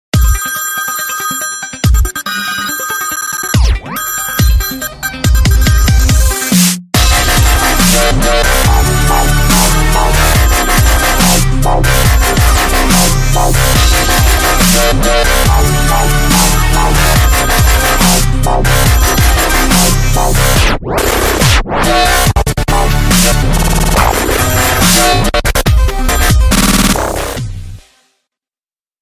old-phone-dubstep_24871.mp3